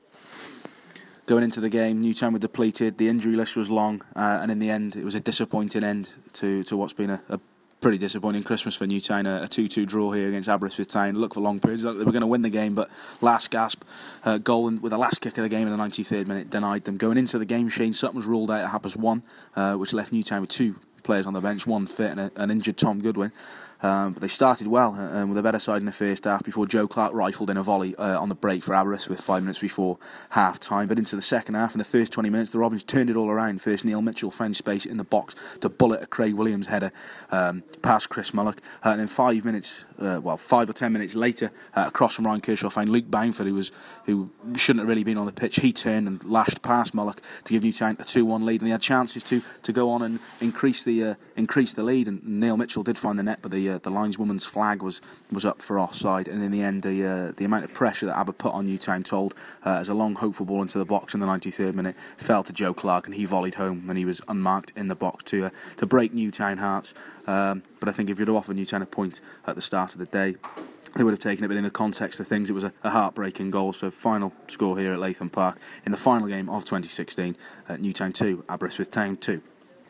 AUDIO REPORT - Robins 2-2 Aberystwyth Town